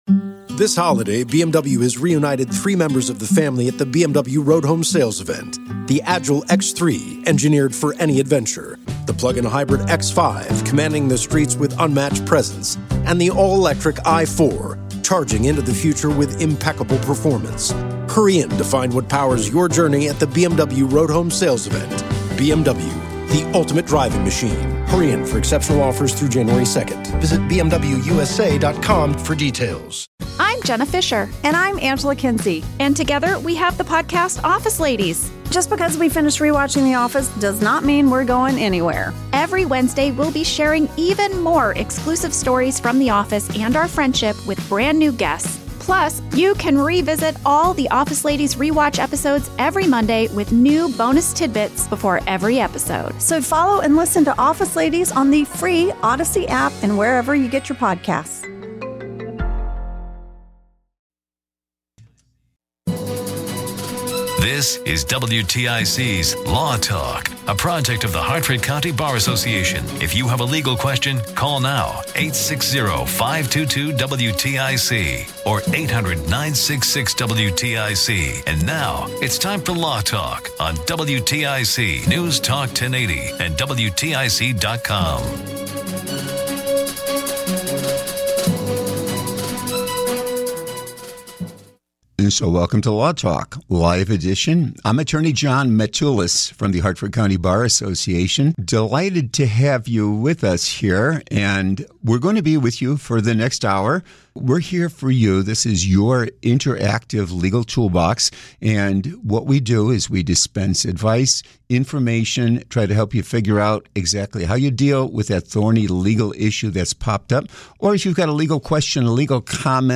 We also addressed a caller's specific copyright question related to their mother’s writings.
Additionally, we answered another caller's inquiry about a vacation buyout.